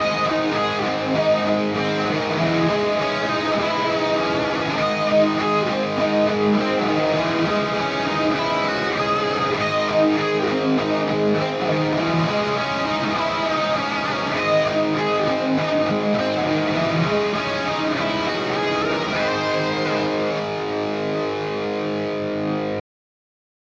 Great Marshall tone!
Last one and the amp was set really Dark because some poeple mentioned that the thought it was an overly bright amp.